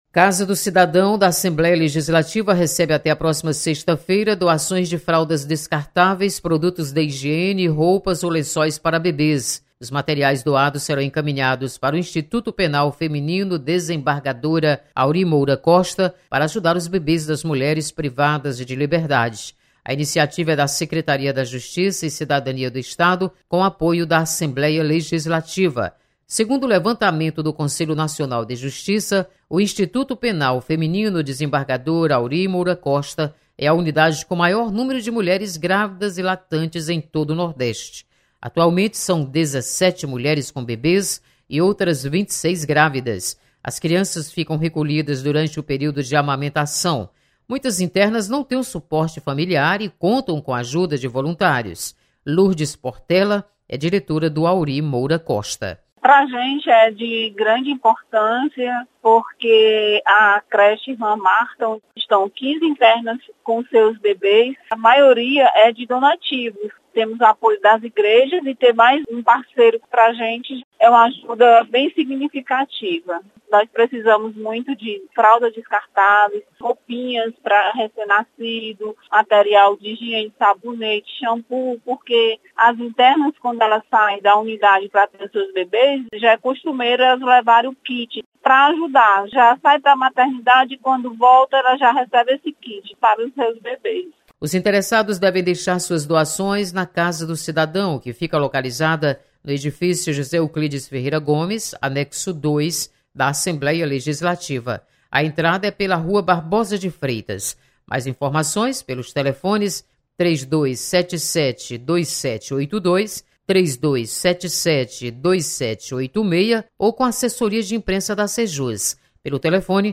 Casa do Cidadão da Assembleia recebe doações para filhos de presidiárias. Repórter